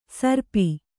♪ sarpi